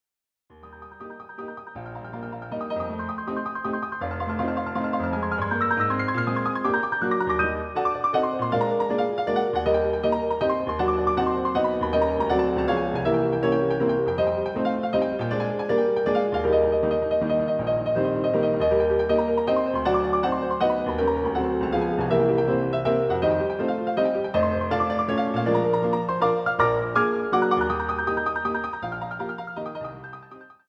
using the stereo sampled sound of a Yamaha Grand Piano